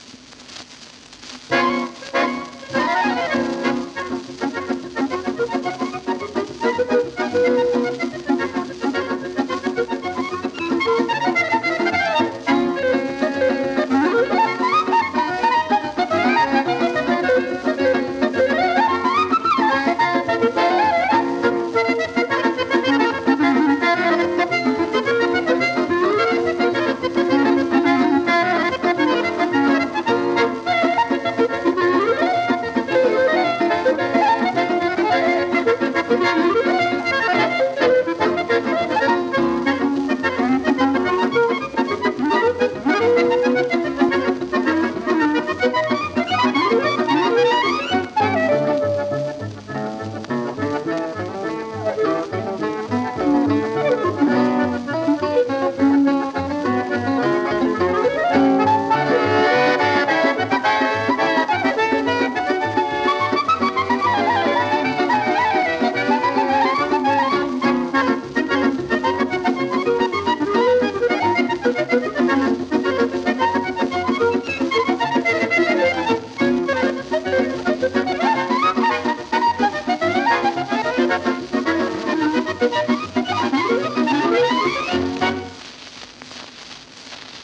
Инструментальный квартет